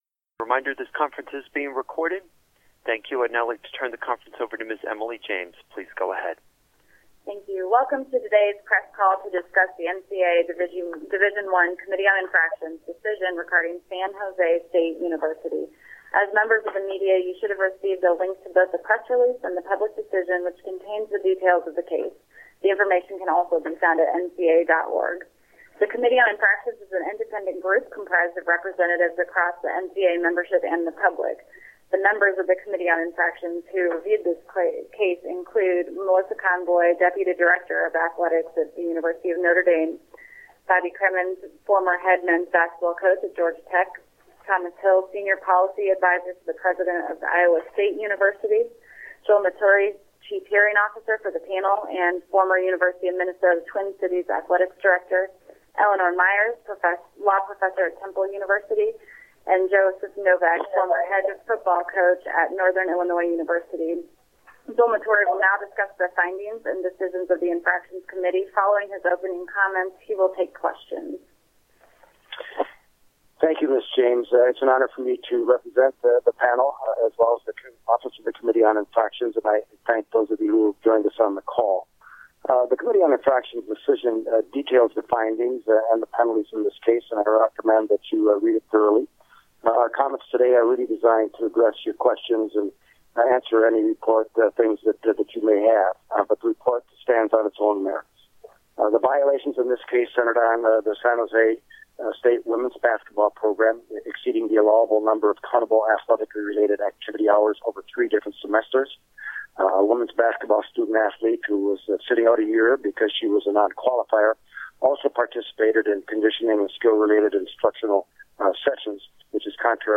NCAA Division I Committee on Infractions Media Teleconference Regarding San Jose State University